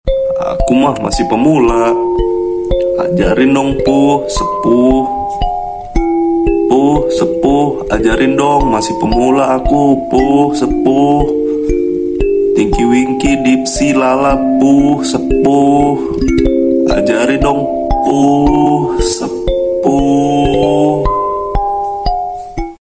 Kategori: Suara viral